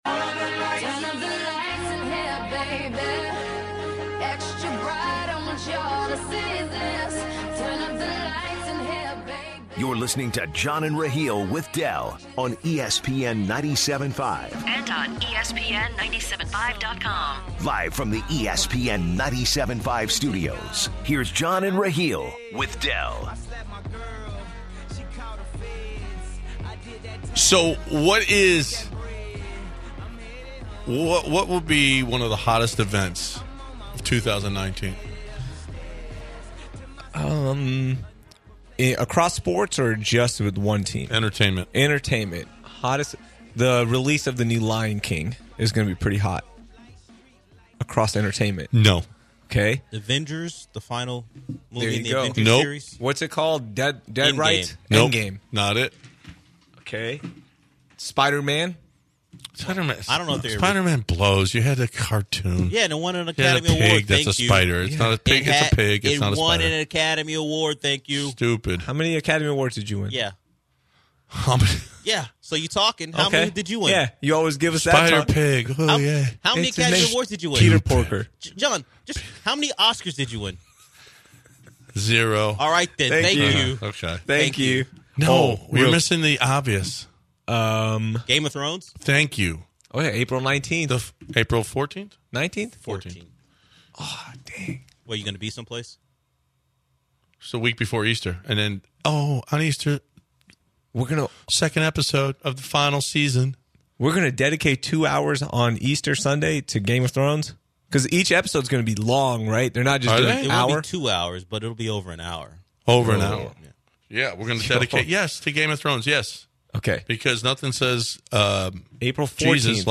MLB Network’s Robert Flores joins the show. The guys go into the Patriots owner’s legal troubles and end in the Astros lack of moves in the offseason.